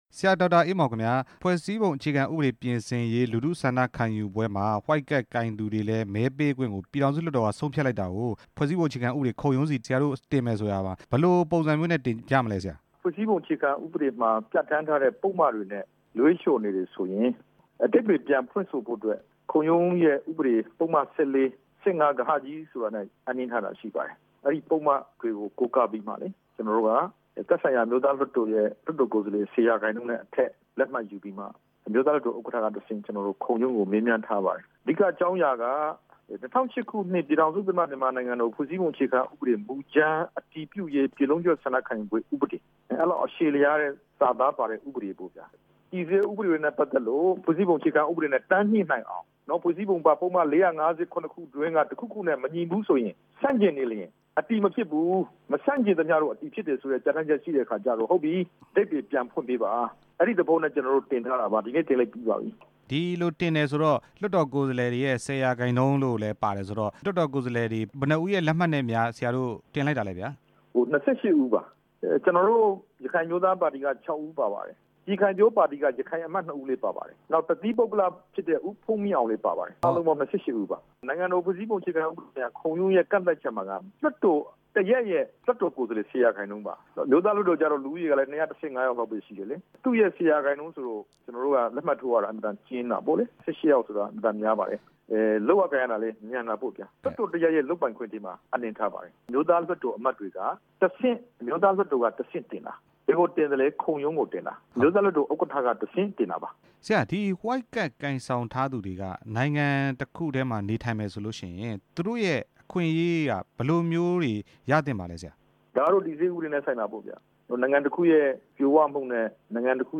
ဒေါက်တာအေးမောင်နဲ့ မေးမြန်းချက်